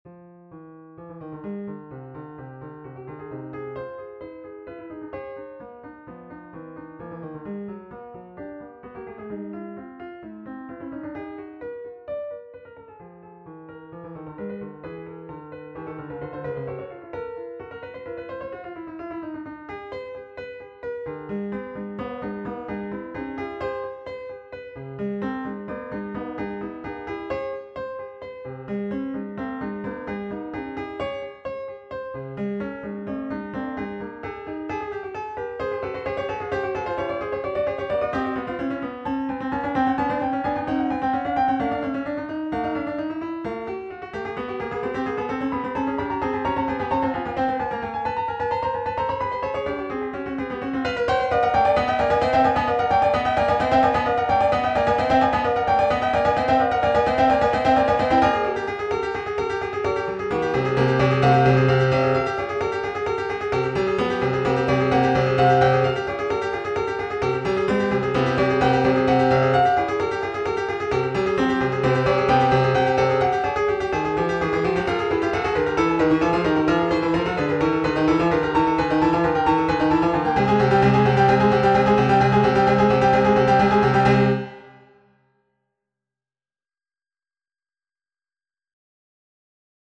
Looking for any comments regarding mastering, finishing touches, etc. I'm using the Steinway D Prelude model.
The piece was then transformed into a piece for 2 12et keyboards which can play quartertones.
acidbach2-acoustic.mp3